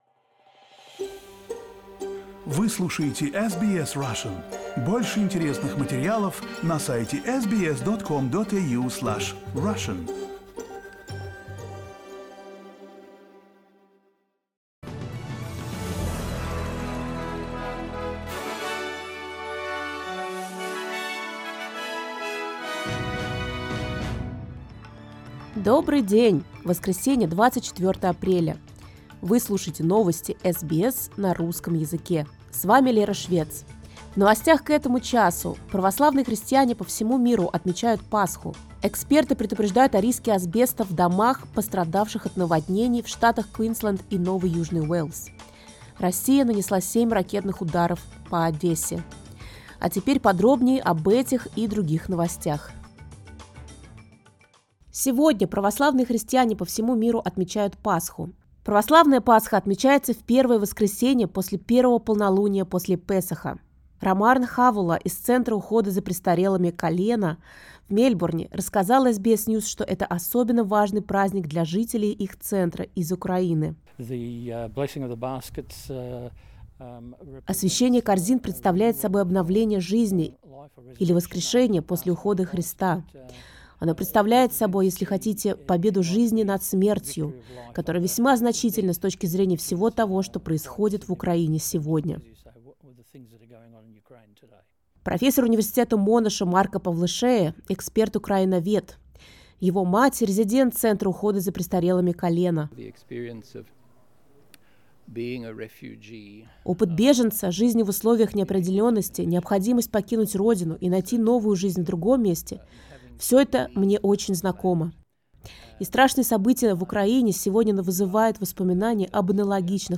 Новости SBS на русском языке — 24.04